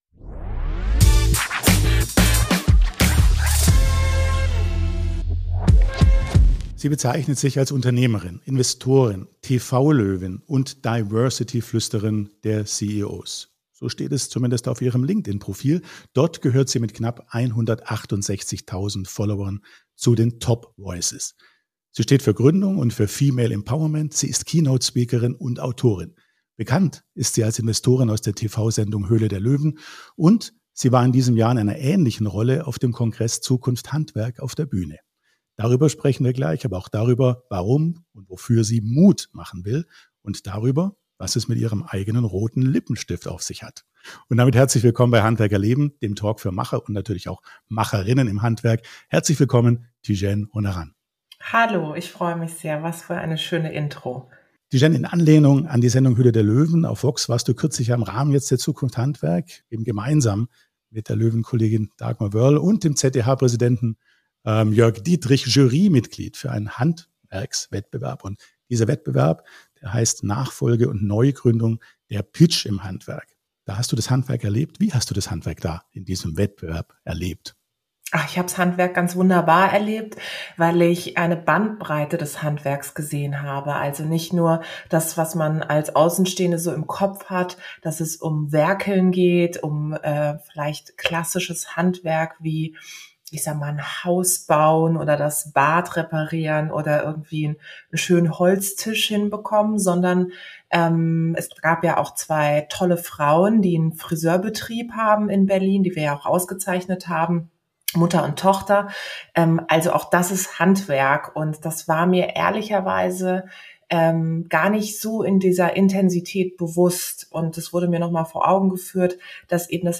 #79 Tijen Onaran, mit Mut und Sichtbarkeit zum Erfolg – wie gelingt das? ~ Handwerk erleben – Der Talk für Macher Podcast